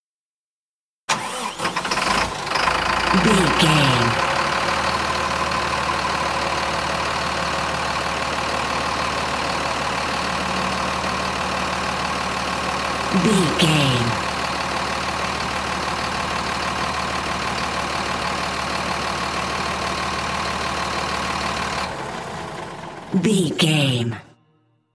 Truck engine start idle
Sound Effects